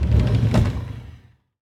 drawer.L.wav